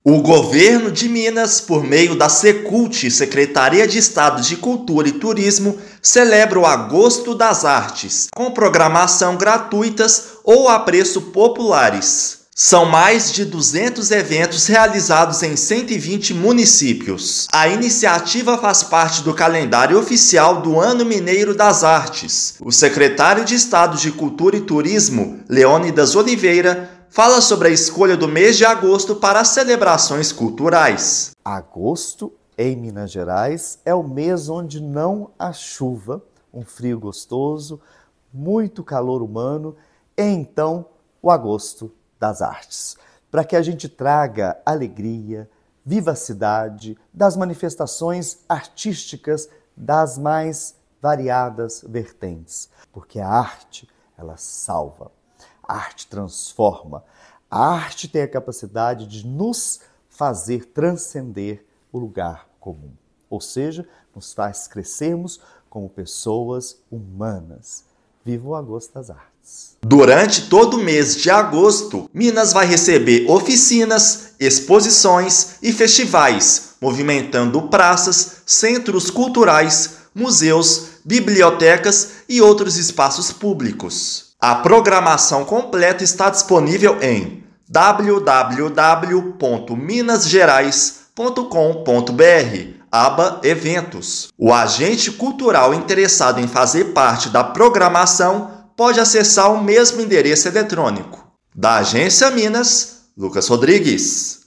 [RÁDIO] Obras do Governo de Minas em rodovias no Sul do estado entram na reta final
Trechos da MG-350 e AMG-1915 passam por melhorias no pavimento e na sinalização. Ouça matéria de rádio.